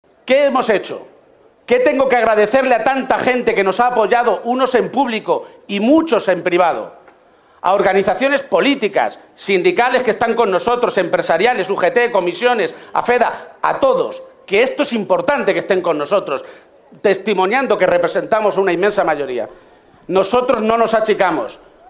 Cerca de tres mil personas acudieron al mitin celebrado en la Caseta de los Jardinillos del Recinto de la Feria de Albacete
Cortes de audio de la rueda de prensa